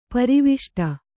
♪ pariviṣṭa